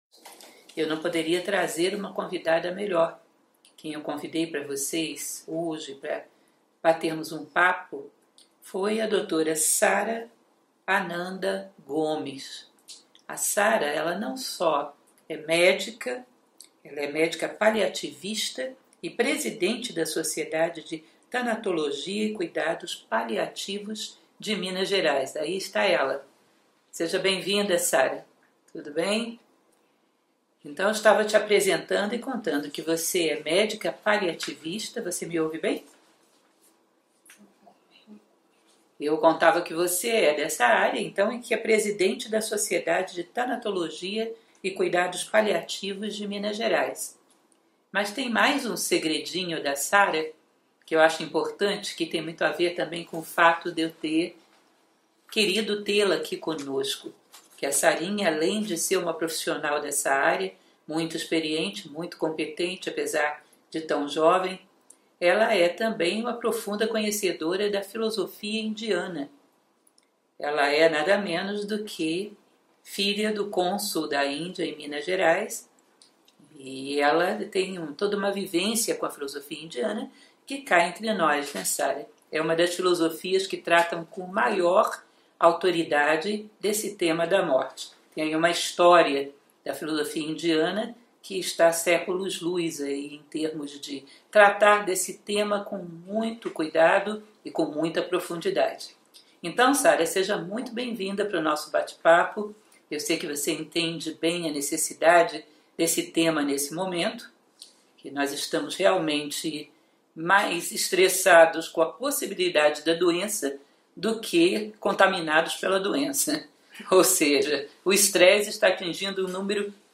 #140 - O ser humano diante da ideia da morte (live editada)